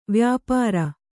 ♪ vyāpāra